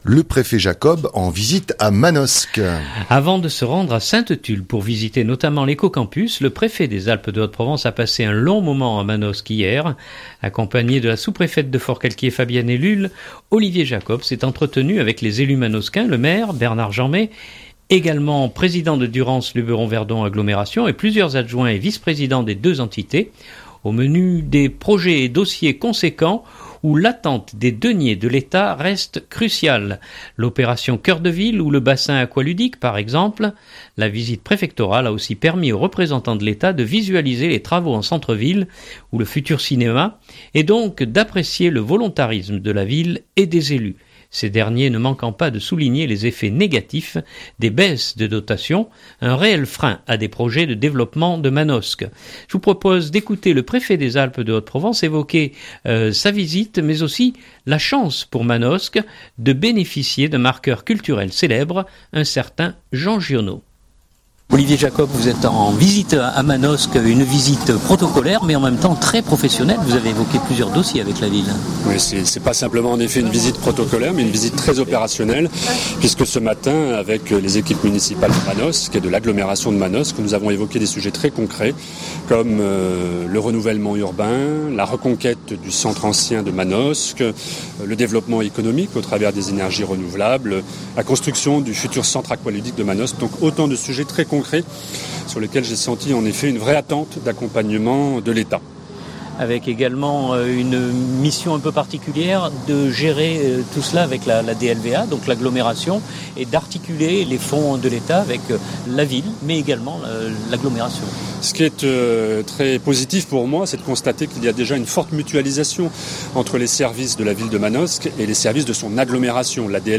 Je vous propose d’écouter le préfet des Alpes de Haute-Provence évoquer sa visite mais aussi la chance pour Manosque de bénéficier d’un marqueur culturel célèbre : un certain Jean Giono.